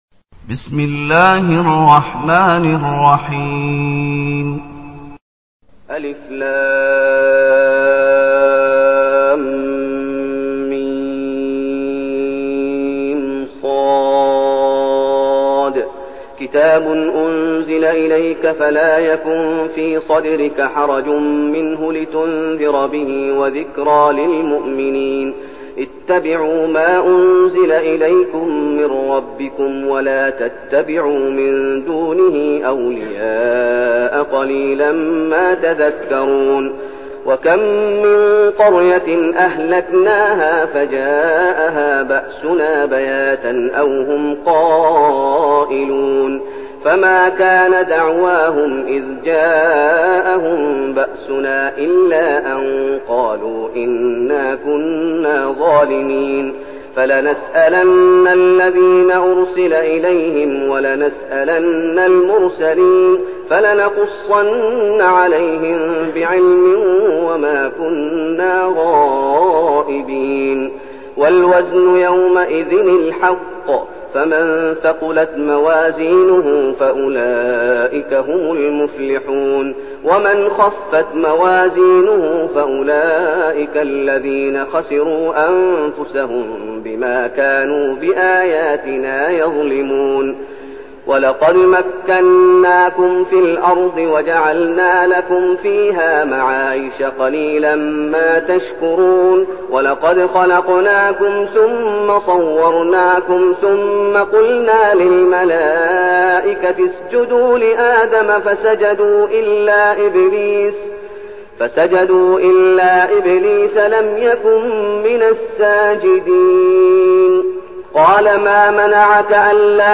المكان: المسجد النبوي الشيخ: فضيلة الشيخ محمد أيوب فضيلة الشيخ محمد أيوب الأعراف